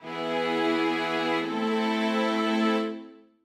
III-VImの連結：ミ・シミソ♯からラ・ドミラ
c1-cadence-DT-Hm.mp3